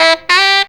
COOL SAX 10.wav